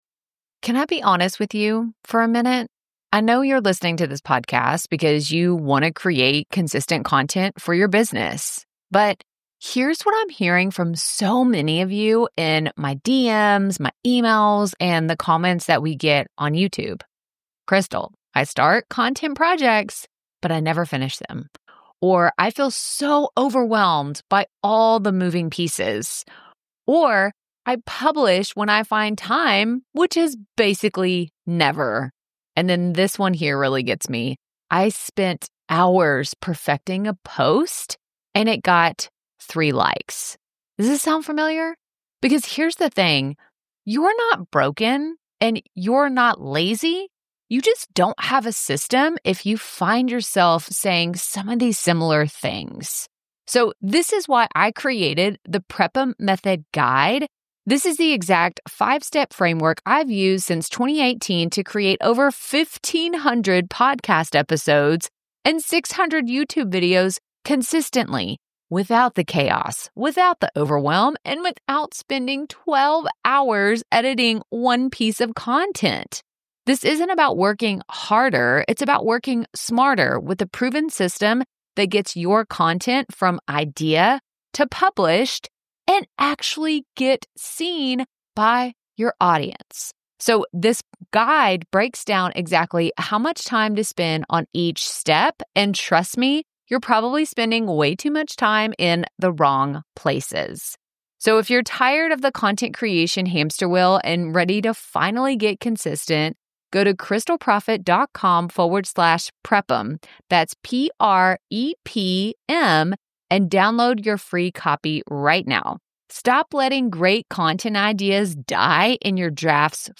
Join us for an enlightening conversation